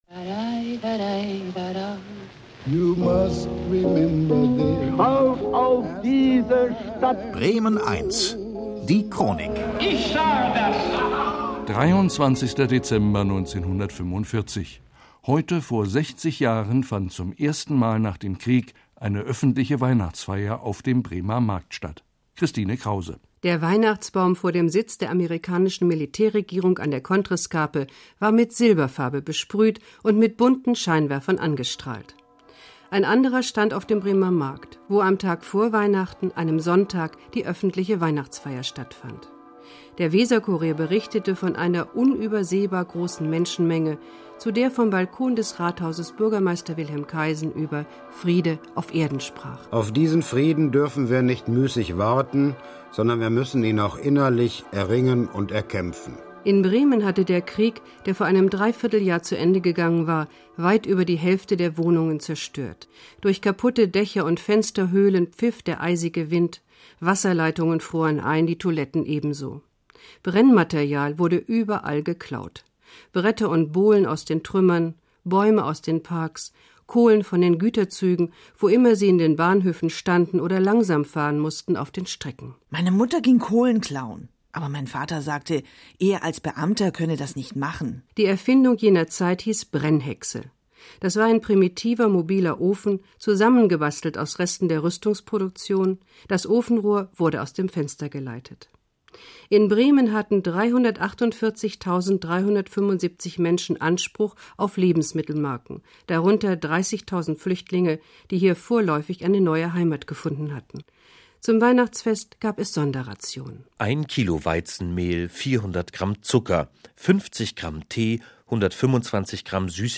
Beitrag aus der Radio-Bremen Sendereihe "AsTime Goes by"